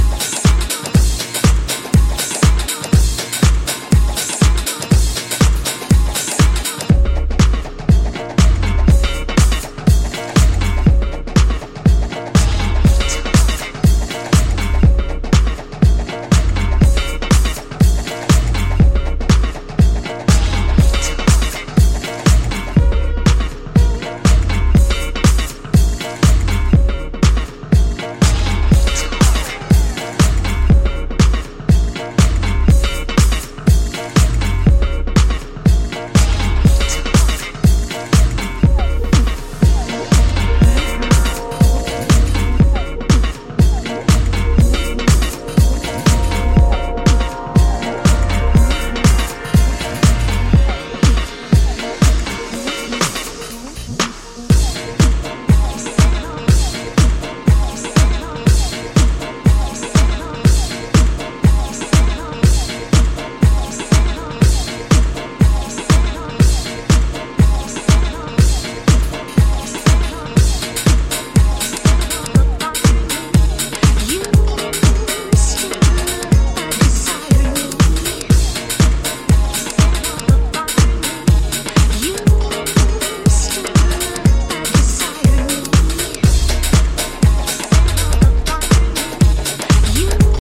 Disco injected house tracks
Good vibes and extatic dancing stuff.